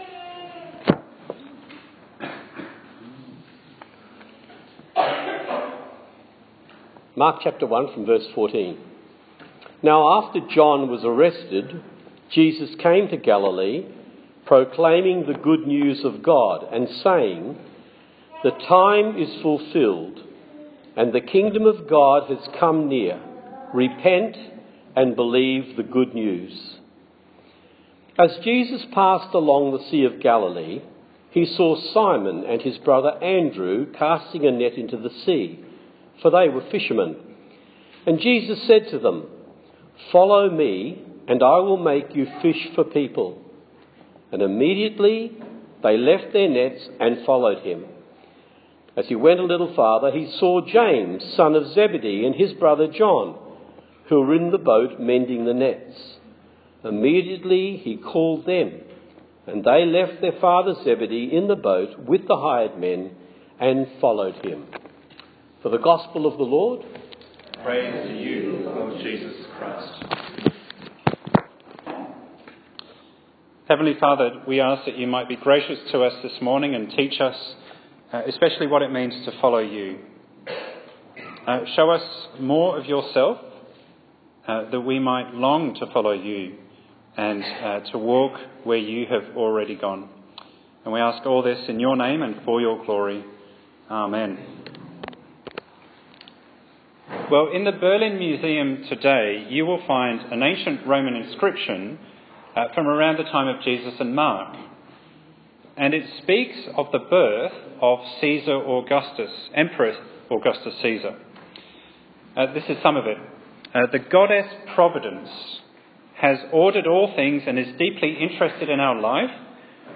Service Type: Sunday morning service